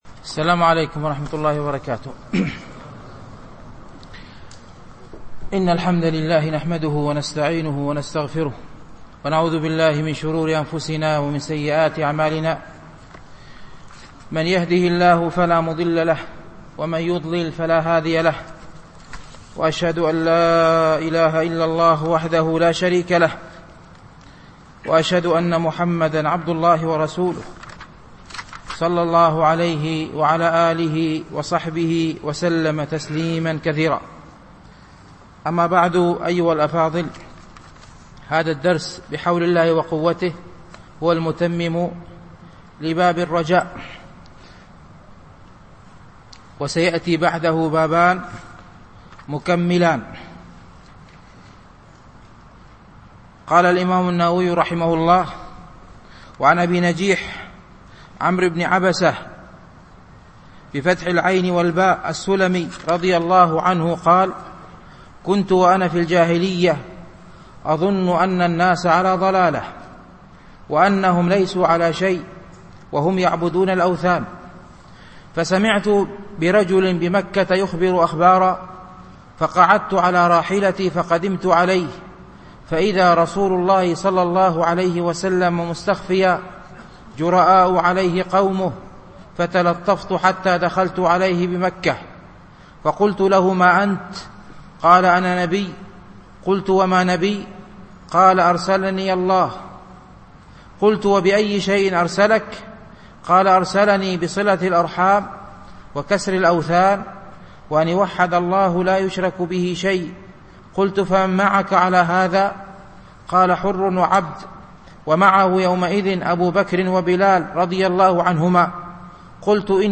شرح رياض الصالحين - الدرس التاسع والعشرون بعد المئة